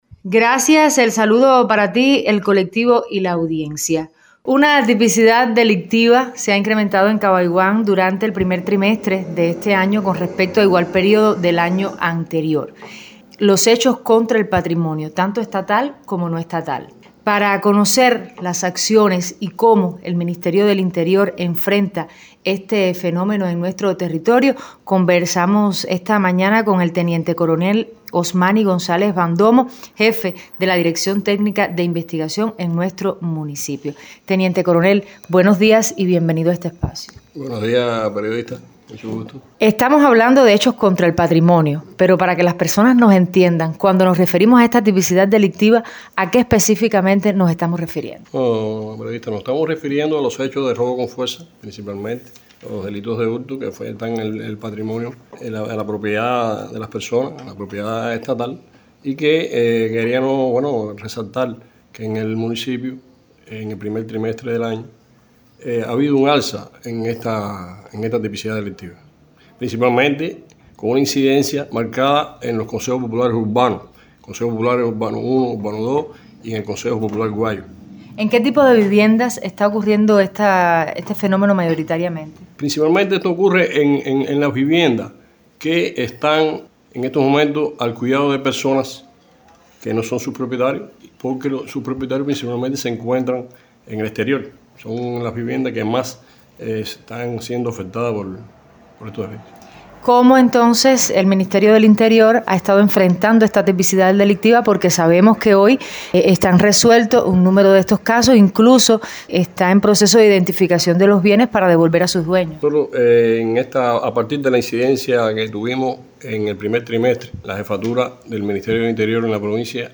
De cómo enfrenta el Ministerio del Interior en el territorio este flagelo versa el siguiente diálogo para la página web de La Voz de Cabaiguán